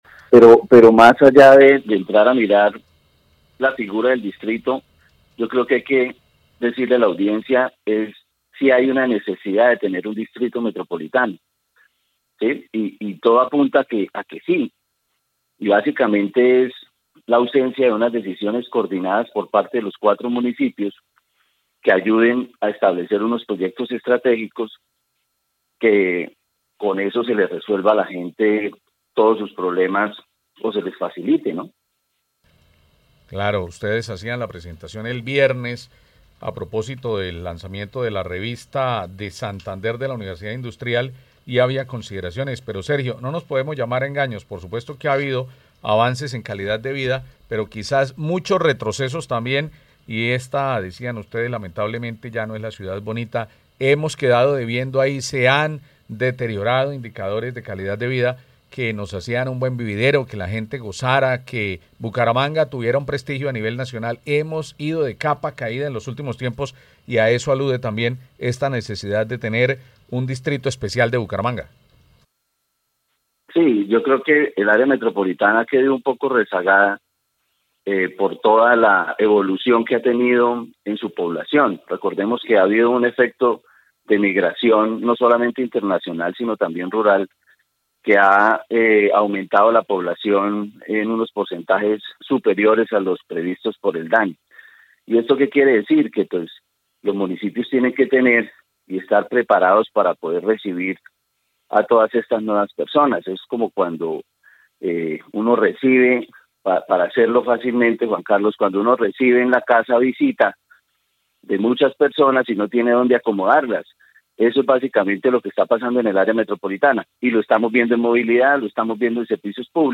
abogado con maestría en Gestión Urbana aseguró en entrevista con Caracol Radio que “la propuesta de consolidar un Distrito Metropolitano en el área metropolitana es una respuesta necesaria ante los retos generados por el acelerado crecimiento urbano y la falta de planificación integral”.